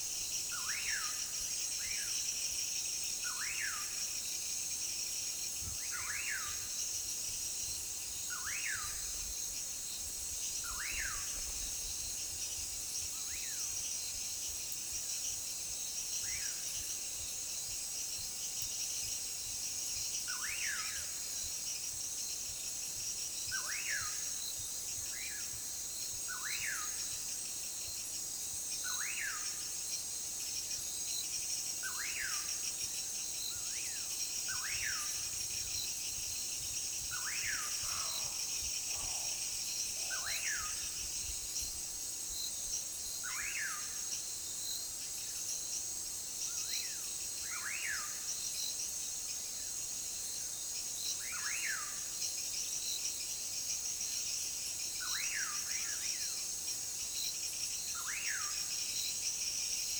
Aves
(Nyctidromus sp)